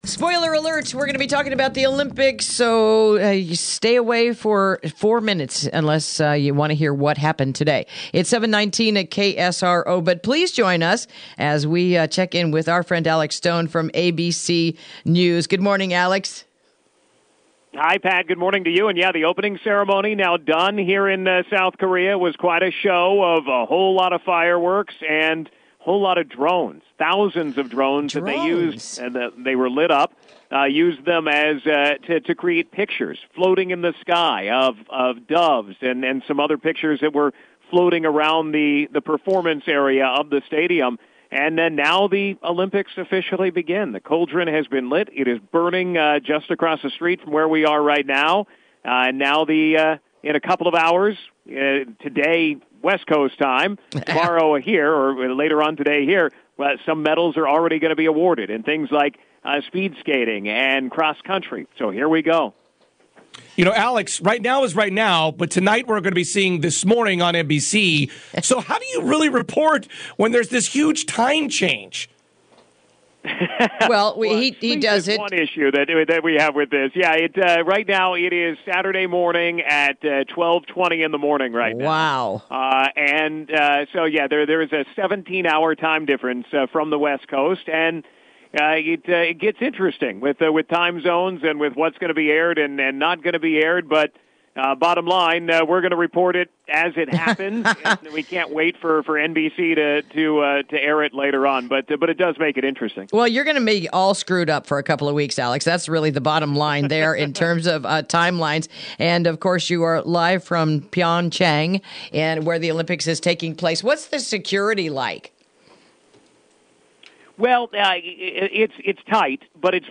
Interview: Preview and Highlights for the Opening Ceremonies of the Winter Olympics